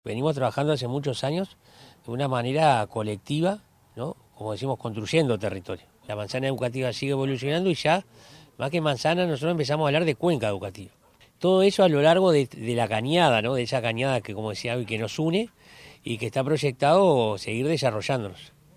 liber_moreno_alcalde_nicolich_0.mp3